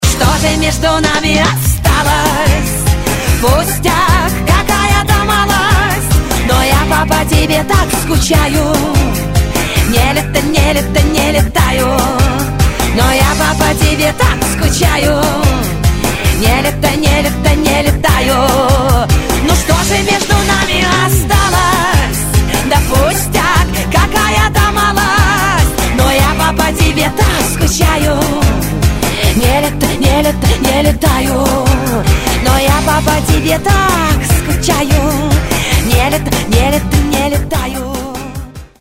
Рингтоны шансон